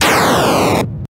But if you want the authentic original Defender arcade machine laser sound, you can right-click the link below and save it to your Assets/Sounds/Weapons folder.
Laser Sound
DefenderLaser.ogg